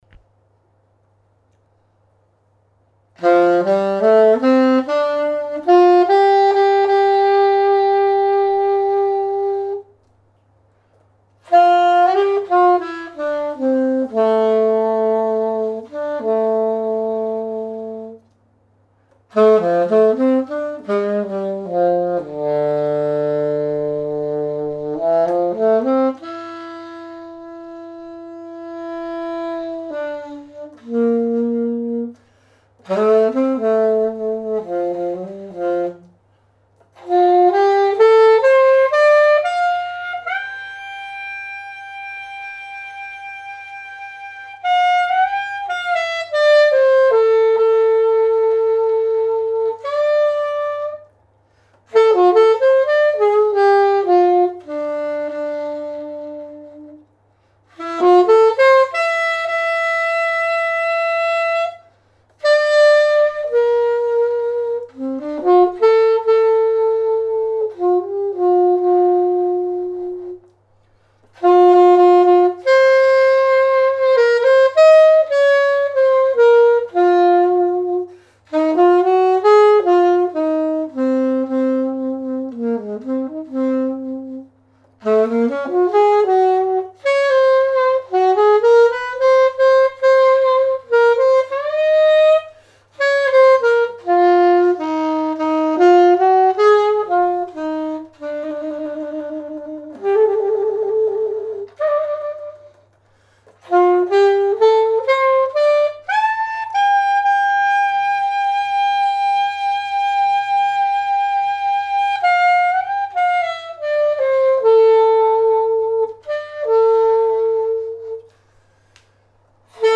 ＡＩＺＥＮは、息の通り、音の立ち上がりが、とても良く、ピッチも下から上まで安定し、バランスのとれたマウスピースだと思います。